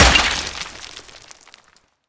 Rupture_Marble_Impacts_06.ogg